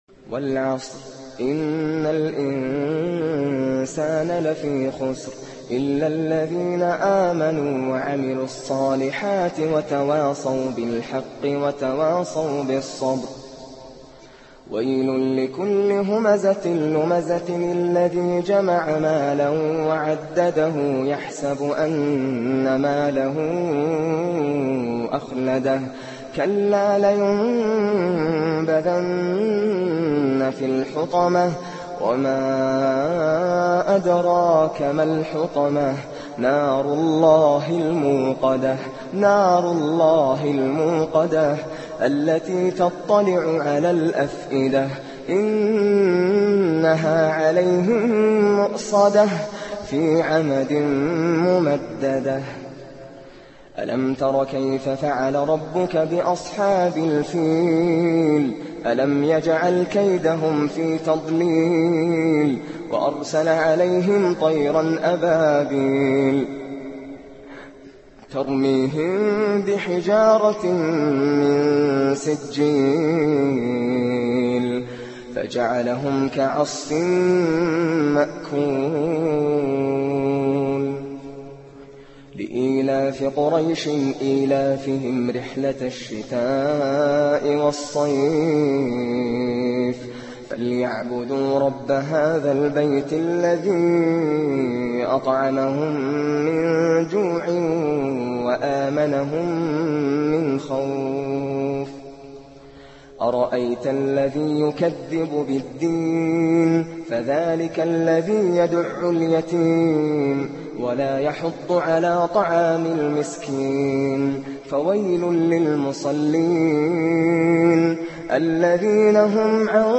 الخشوع التاااااام مــع القارئ◄ نــاصــر الــقــطـامــي►  [أجمل القراءات موضوع متجدد] - صفحة 3 - بريدة ستي